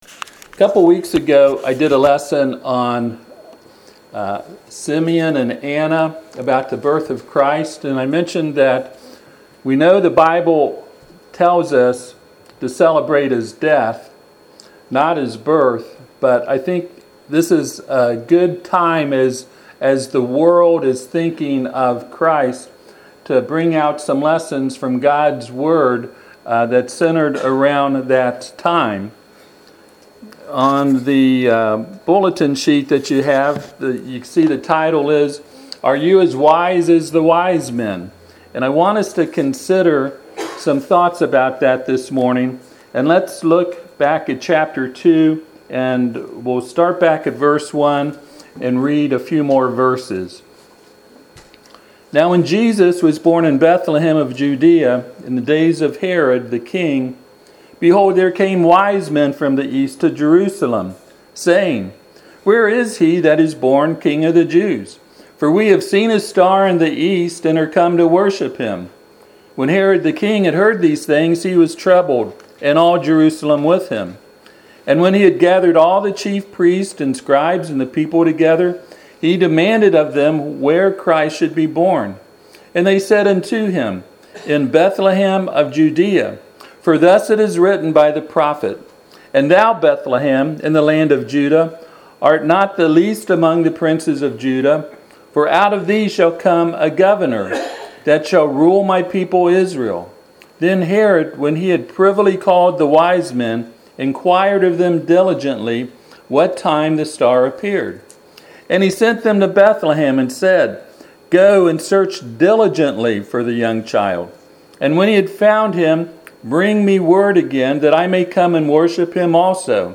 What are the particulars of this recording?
Passage: Matthew 2:1-12 Service Type: Sunday AM « Regulating Spiritual Speech Sermon on the Mount